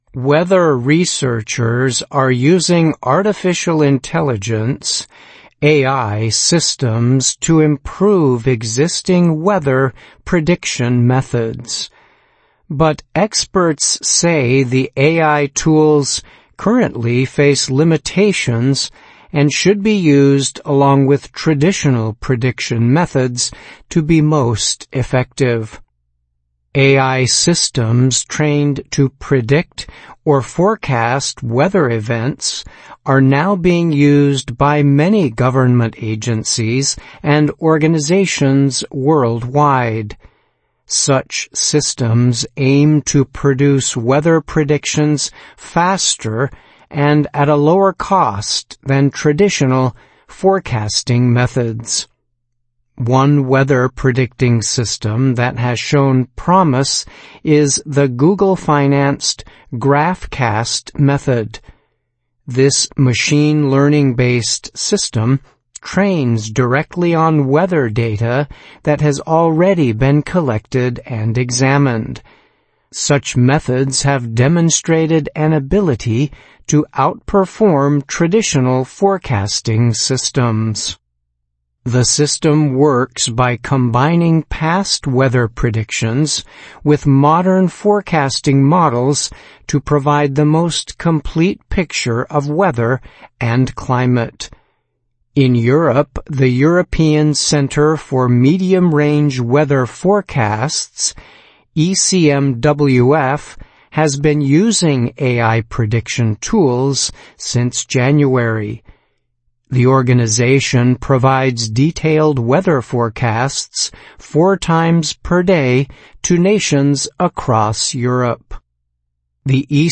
VOA慢速英语逐行复读精听提高英语听力水平